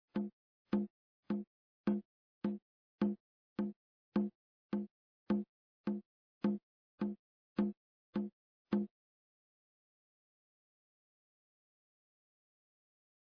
A one idea, repeated and looped into a rhythm is a nice way to start things off.
Here's a couple of one bar rhythms repeated by style.